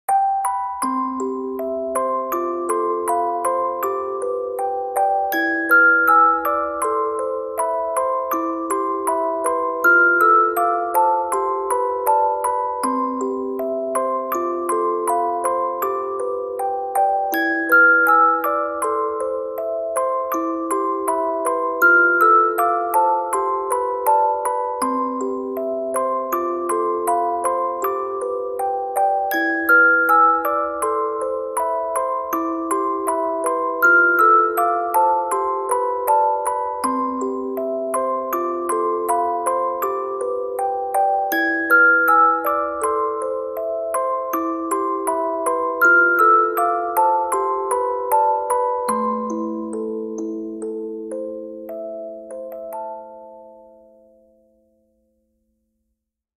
applause.ogg